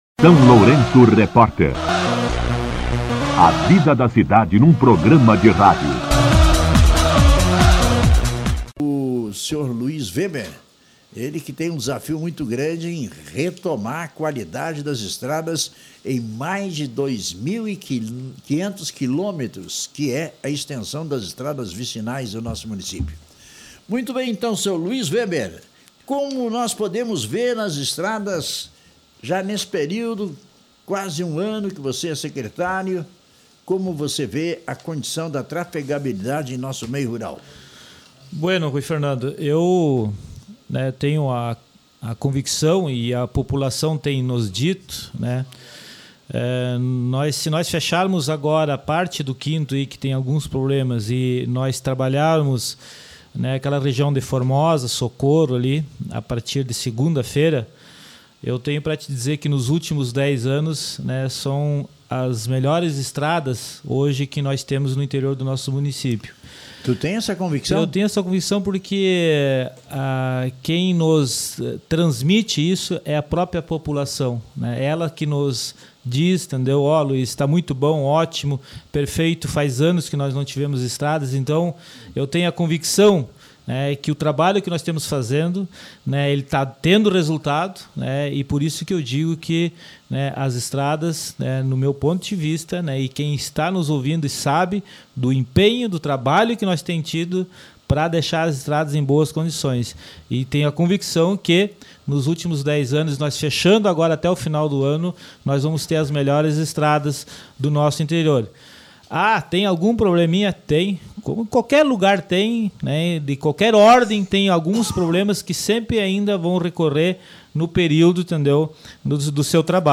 eNTREVISTA COM O Secretário de Desenvolvimento Rural, Luis Weber